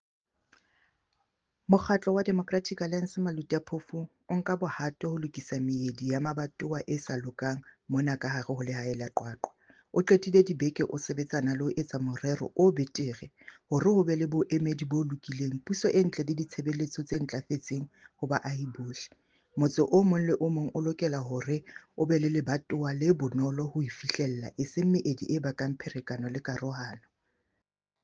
Sesotho soundbite by Cllr Ana Motaung.